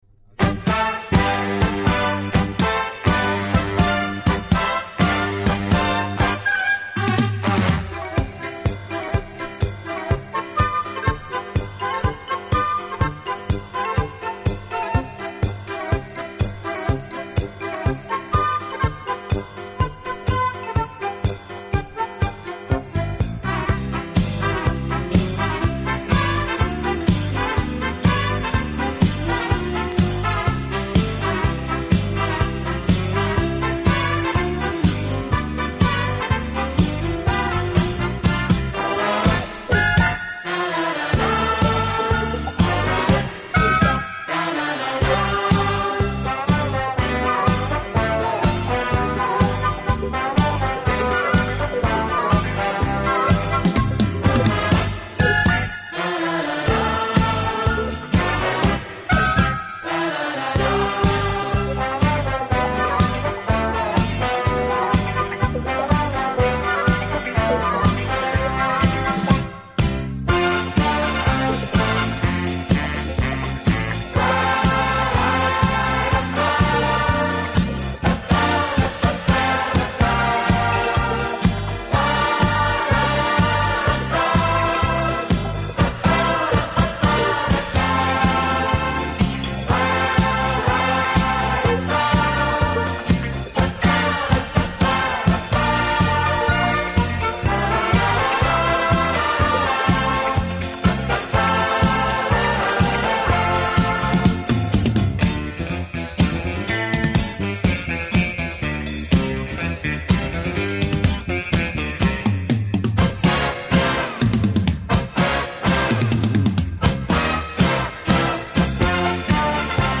有动感，更有层次感；既有激情，更有浪漫。
02 Amboss Polka